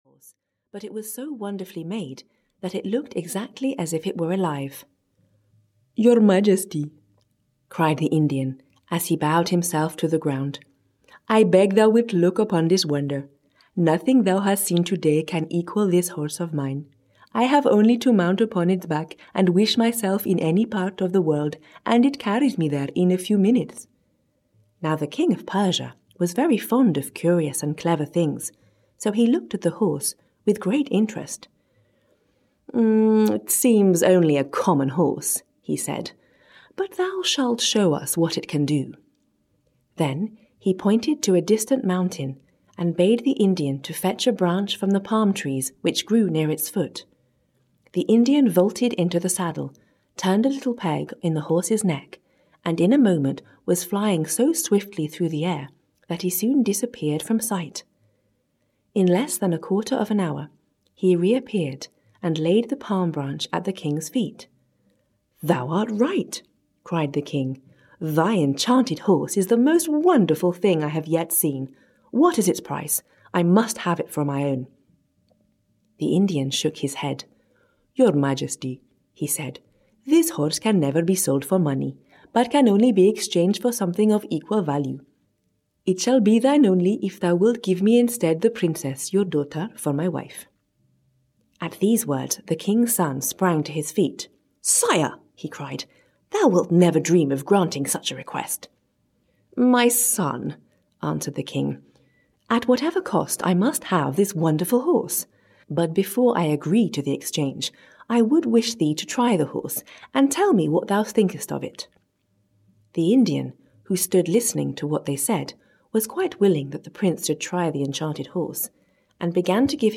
Audio kniha10 Stories for Kids to Ignite Their Imagination (EN)
This compilation includes folk tales, classic tales by Charles Perrault and Hans Christian Andersen, and stories from the Arabian Nights, narrated in a warm and lively way: The Enchanted Horse; the Story of Peter Pan, by James Barrie; The Master Cat, or Puss in Boots; Snow Drop and the Seven Dwarves; Cinderilla, by Charles Perrault; Ali Baba and the Forty Thieves; Sinbad the Sailor; The Frog Prince; Sleeping Beauty in the Woods, and The Fairies, by Charles Perrault.